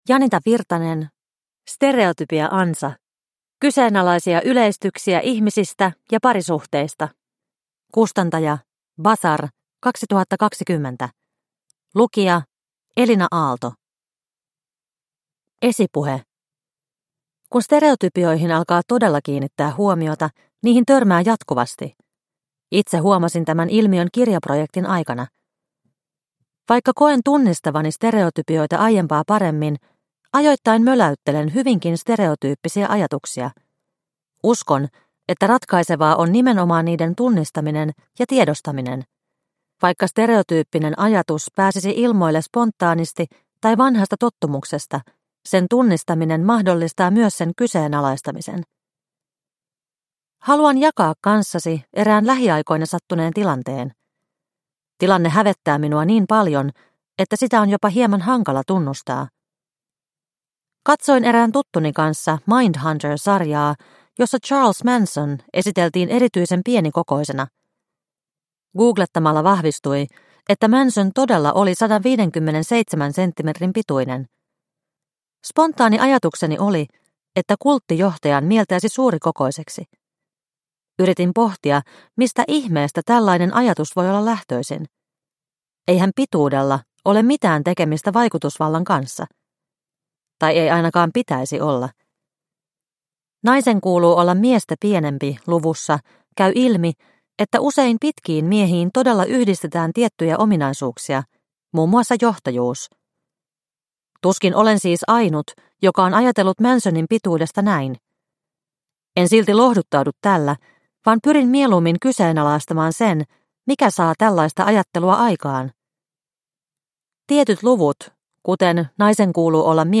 Stereotypia-ansa – Ljudbok – Laddas ner